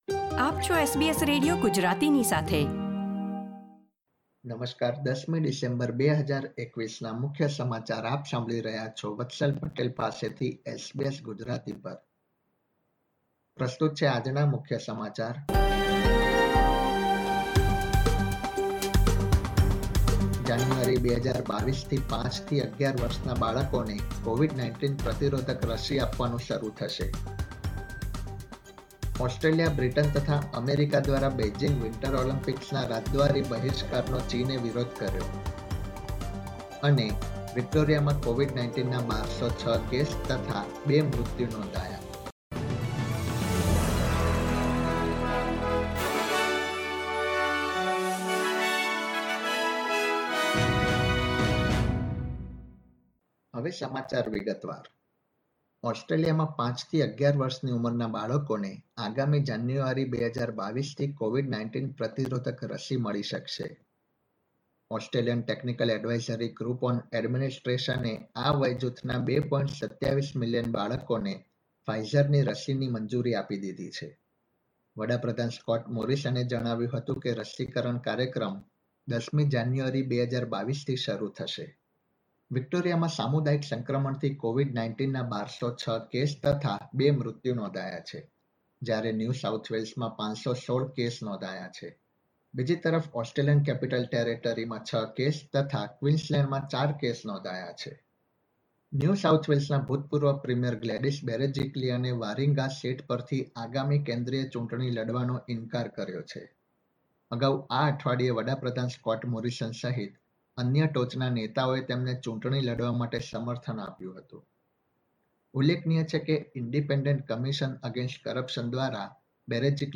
SBS Gujarati News Bulletin 10 December 2021